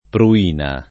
pruina [ pru- & na ] s. f.